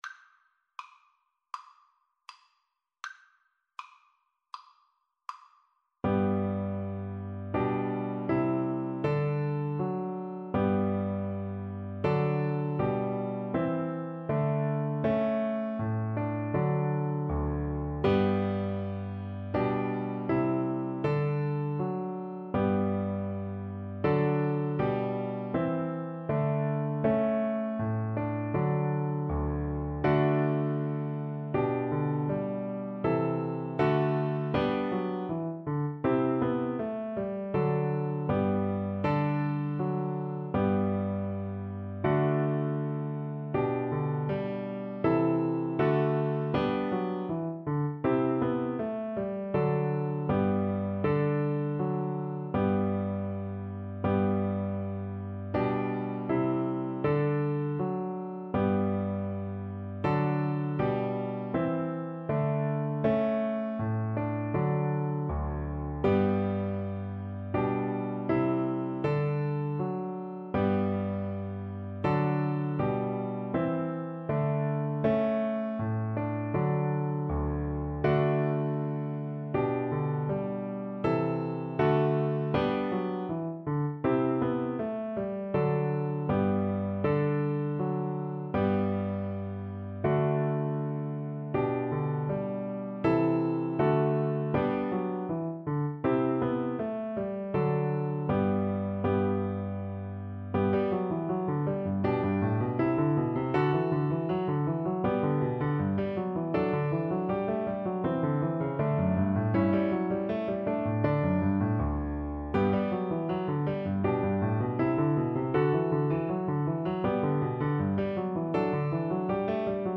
4/4 (View more 4/4 Music)
Allegretto =80